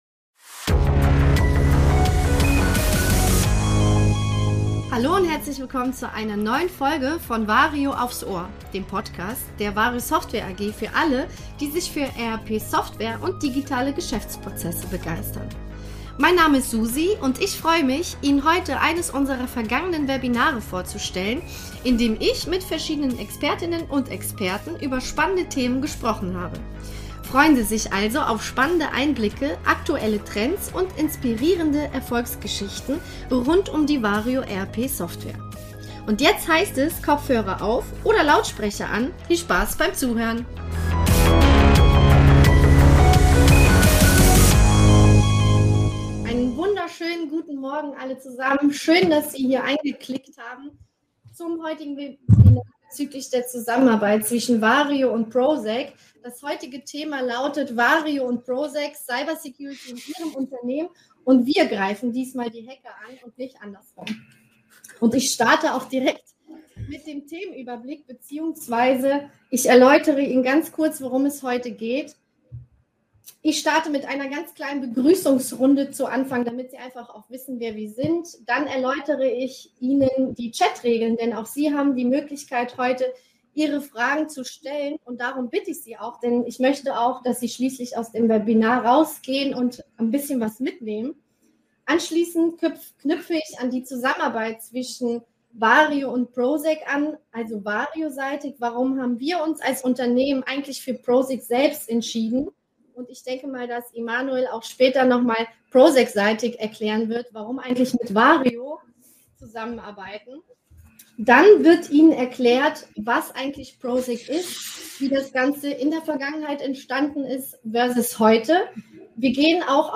In dieser Podcast-Episode nehmen wir Sie mit in unser vergangenes Webinar, in dem es um ein hochaktuelles Thema ging: Cybersicherheit in Unternehmen – und wie VARIO dabei mit dem IT-Sicherheitsunternehmen ProSec zusammenarbeitet.